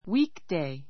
weekday A2 wíːkdei ウィ ー ク デイ 名詞 平日, ウィークデー ⦣ 土曜日と日曜日以外の日. week ❷ My father is busy on weekdays.